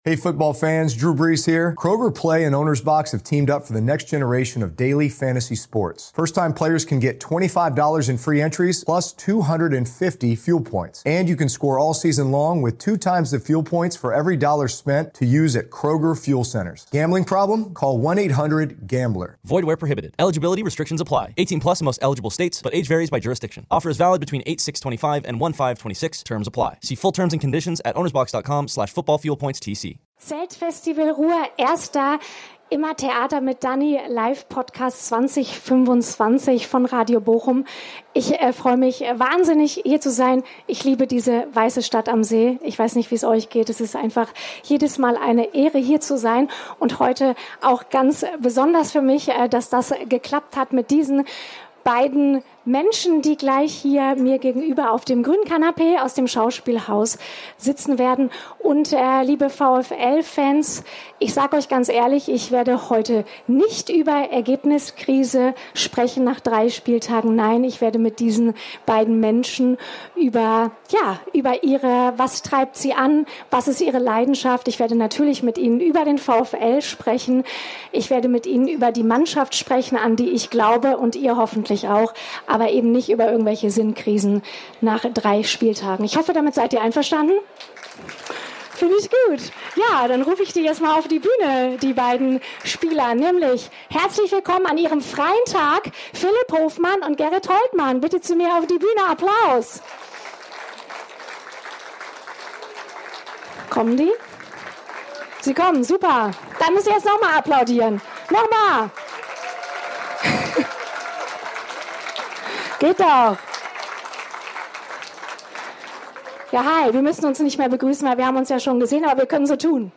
Zum Auftakt waren die beiden VfL Bochum-Spieler Philipp Hofmann und Gerrit Holtmann zu Gast. Was für Rituale die beiden vor Spielen haben, warum Hofmann Fan der Flippers ist und was sie sich von den VfL Fans wünschen - darum ging es unter anderem. Und dass die beiden auch in der Kabine nebeneinander sitzen und sich super verstehen, haben sie dann noch bei einer spontanen Runde Tabu gezeigt.